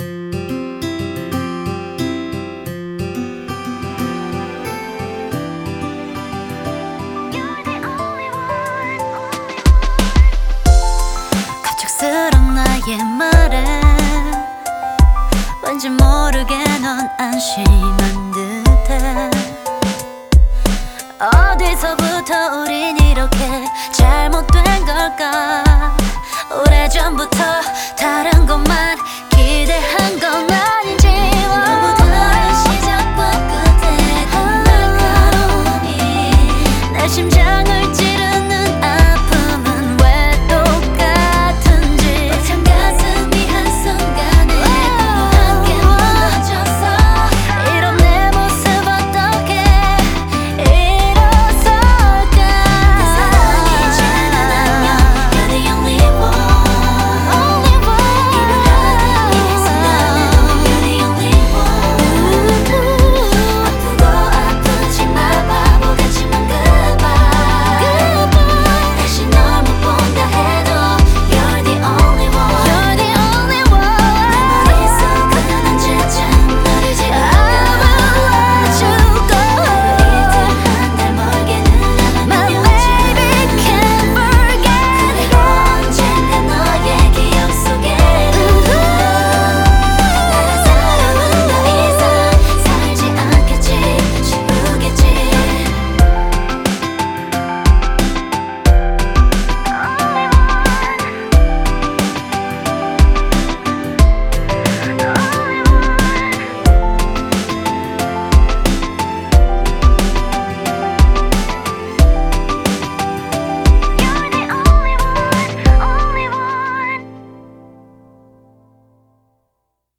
BPM90
Audio QualityPerfect (High Quality)
Instruments prominently featured: Piano, Strings, Guitar